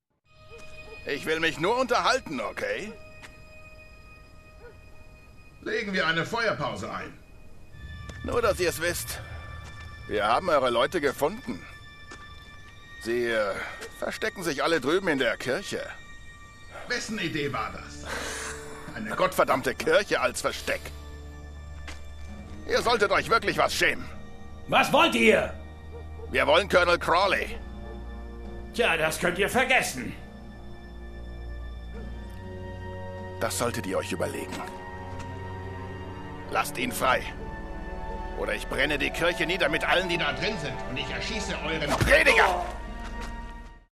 Sprachproben
Männlich
Mezzo-Sopran / Bariton / Mittel
Alt / Bass / Tief
Aber auch Sachtexte z.B. für Imagefilme, E-Learning, Werbung oder Dokumentationen, erwecke ich durch meine warme, sonore, vertrauenerweckende Stimme zum Leben.